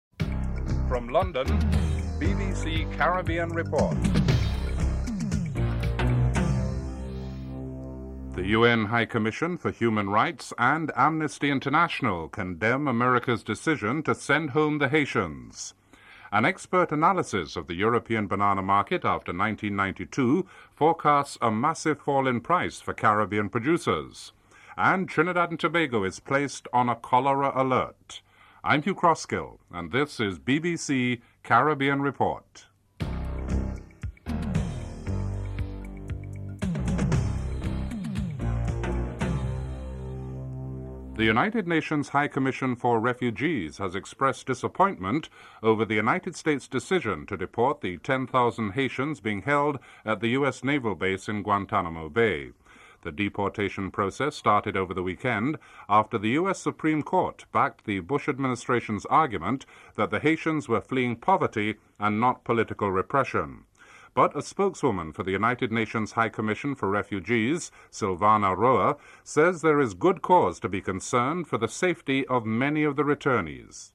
1. Headlines (00:00-00:40)
6. Report on the cholera alert for Trinidad and Tobago due to the epidemic in Latin America (07:16-07:46)